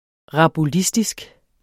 Udtale [ ʁɑbuˈlisdisg ]